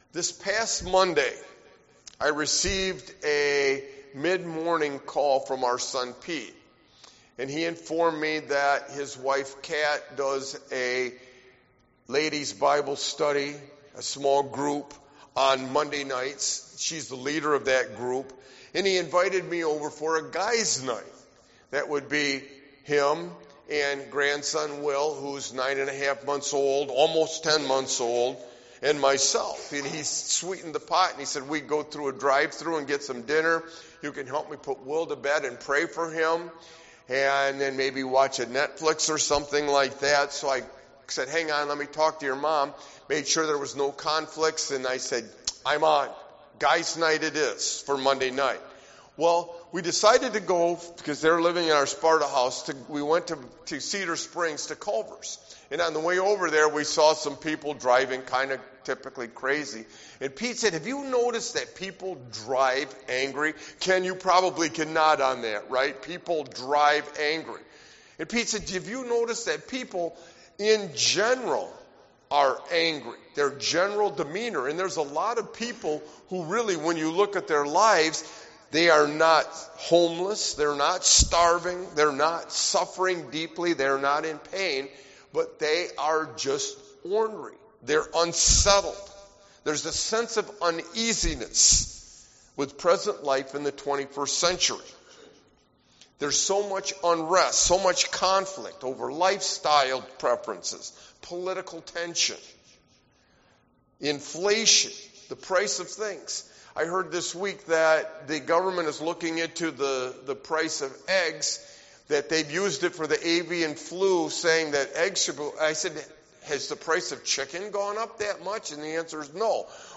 Sermon Walking With Jesus through Difficulty and Loss 4